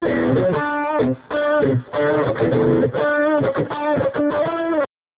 GUITAR LOOPS - PAGE 1 2 3 4